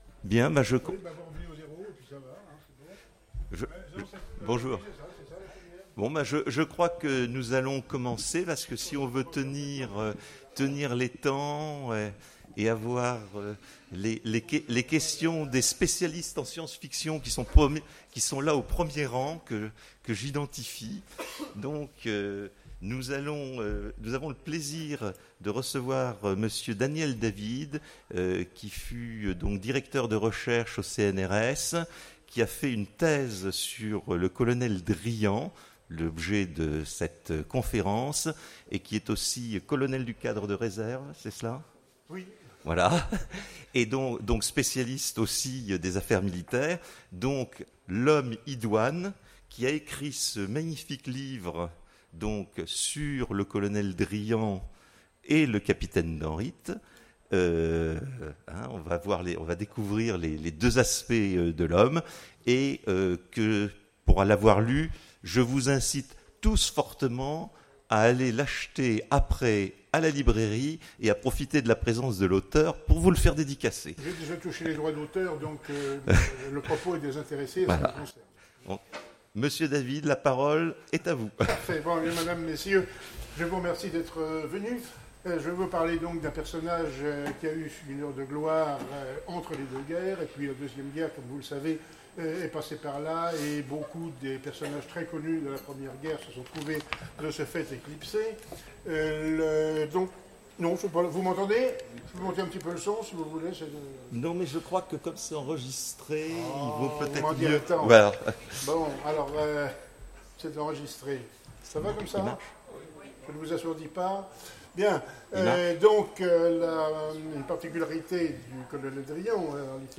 Imaginales 2016 : Conférence Du Colonel Driant au Capitaine Danrit